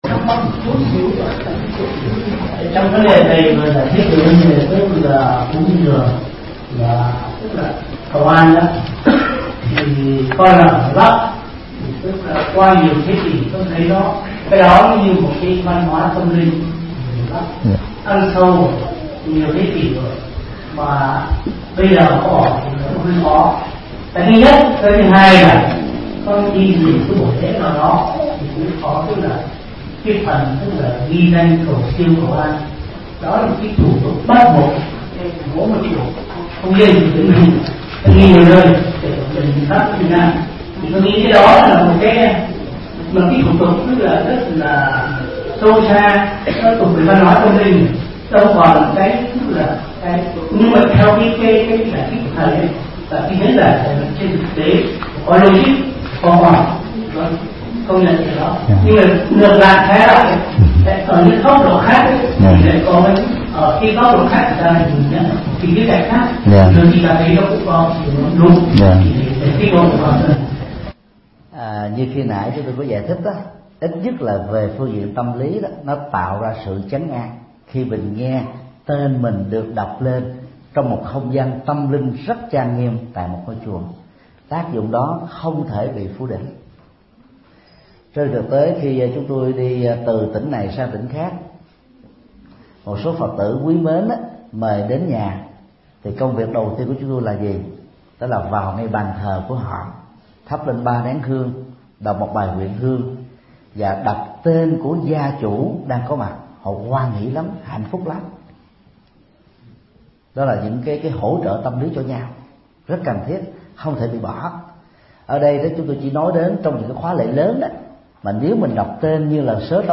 Vấn đáp: Tác dụng tâm lý người cầu an, cầu siêu